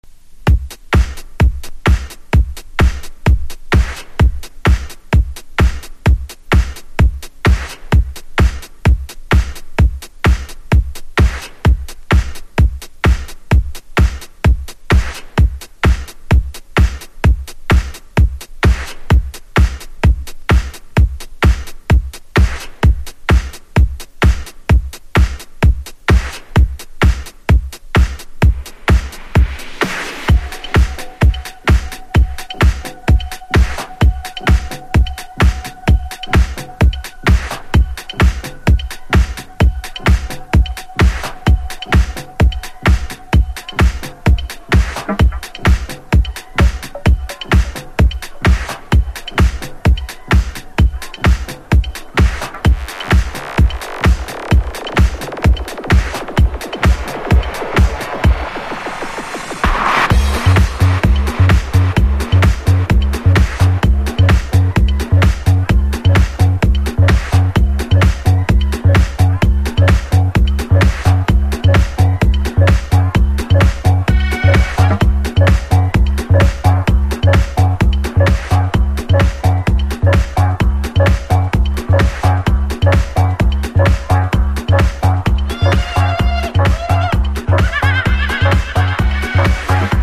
• HOUSE
2007年イビザ・アコーディオン・ハウス・アンセム。